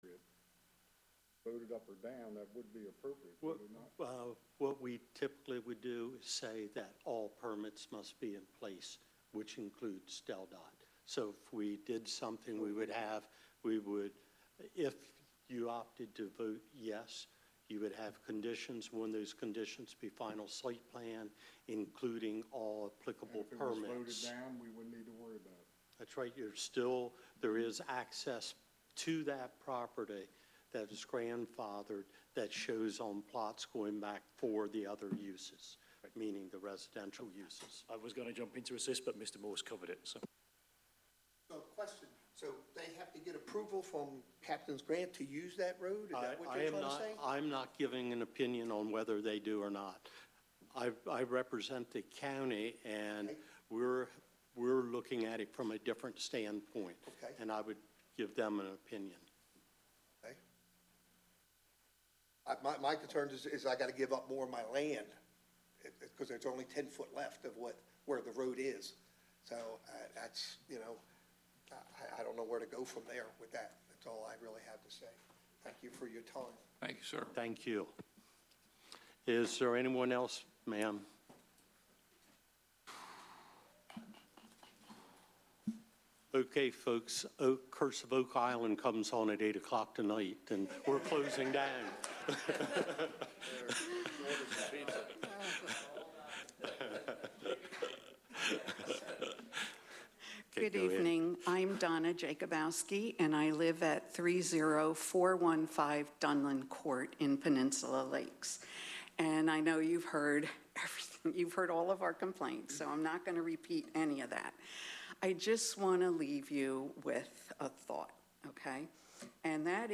County Council Meeting | Sussex County
Meeting location: Council Chambers, Sussex County Administrative Office Building, 2 The Circle, Georgetown